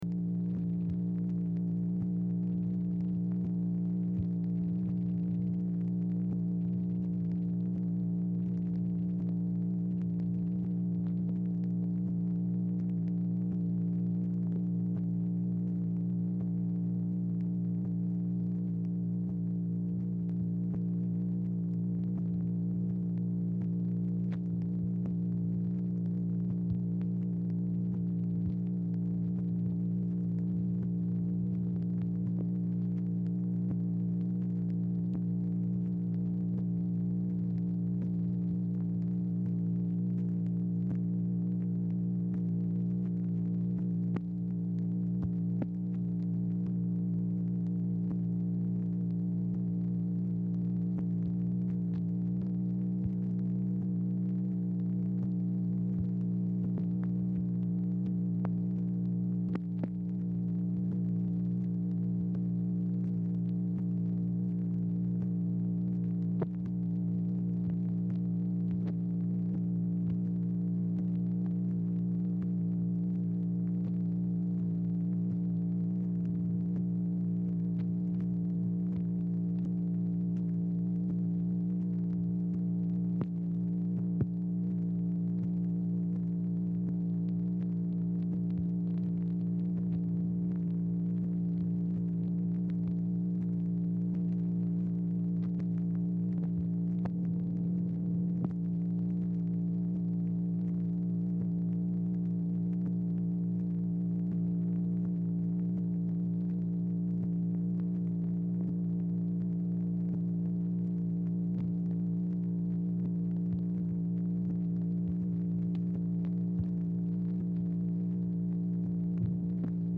Telephone conversation # 4283, sound recording, MACHINE NOISE, 7/20/1964, time unknown | Discover LBJ
Format Dictation belt
Specific Item Type Telephone conversation